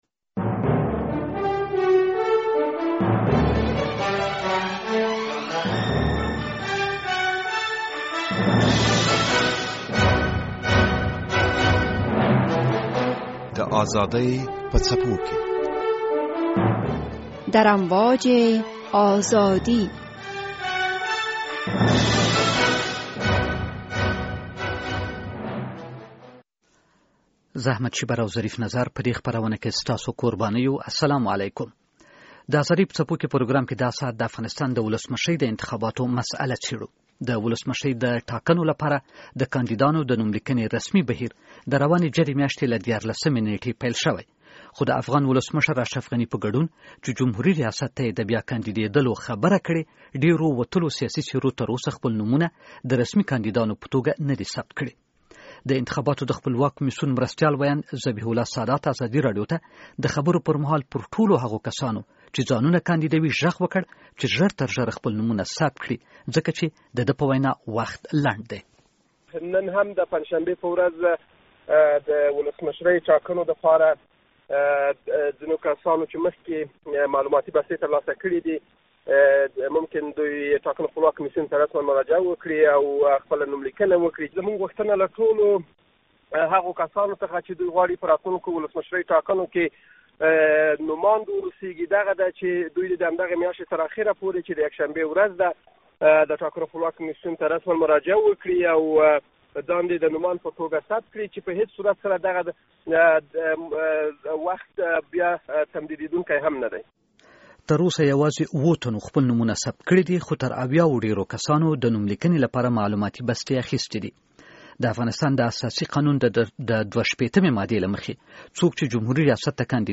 د بحث لومړۍ برخه